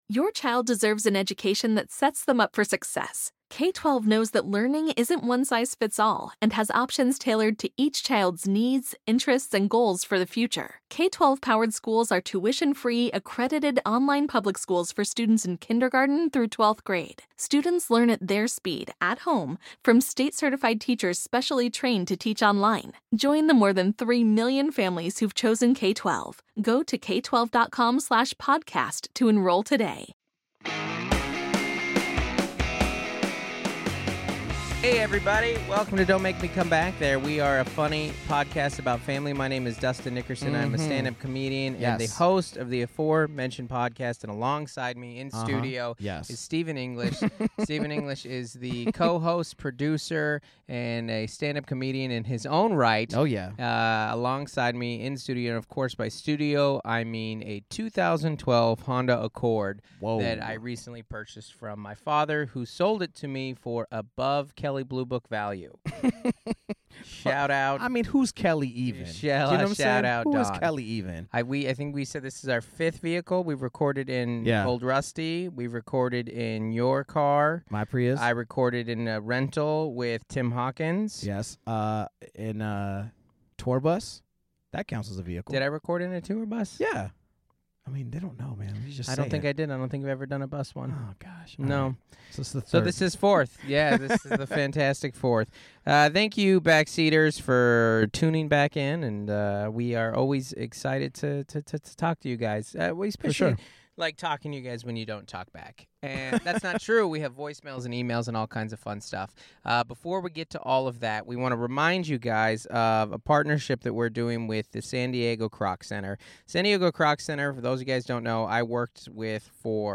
Plus, hilarious listener voicemails and emails that 'mightcould' make your day.